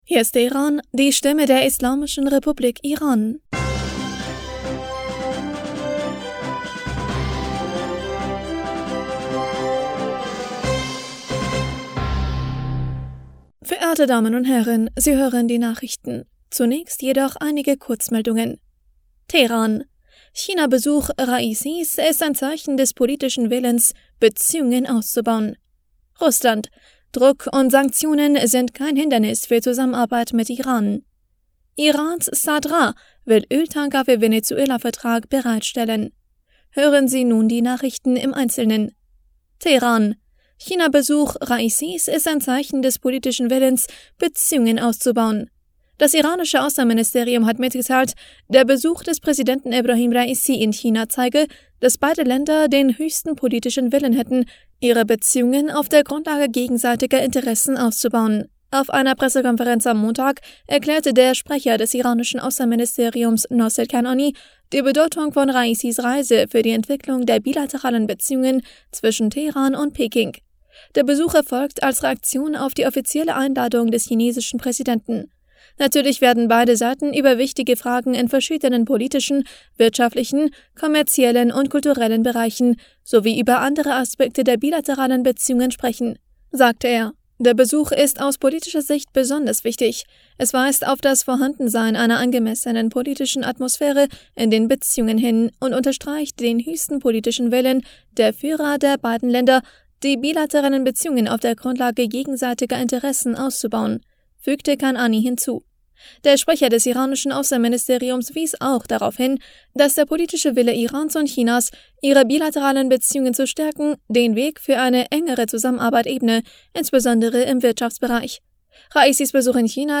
Nachrichten vom 14. Februar 2023